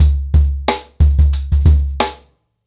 TrackBack-90BPM.39.wav